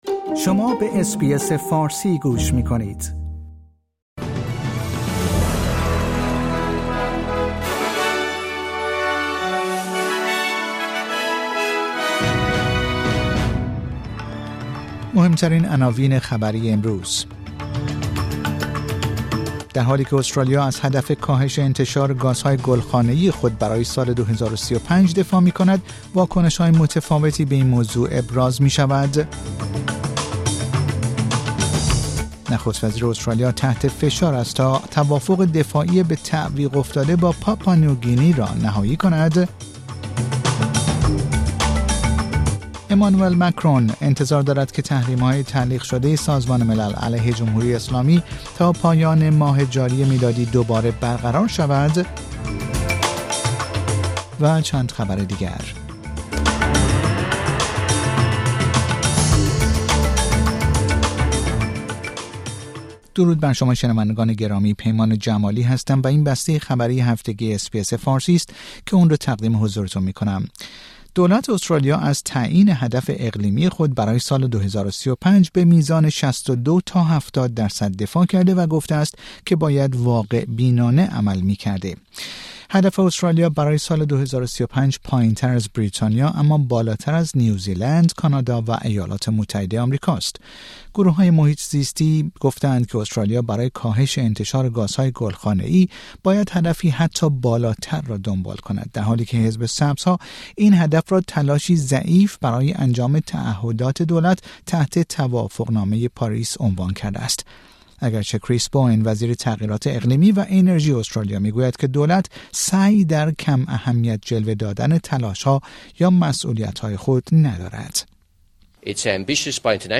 در این پادکست خبری مهمترین اخبار هفته منتهی به جمعه ۱۹ سپتامبر ارائه شده است.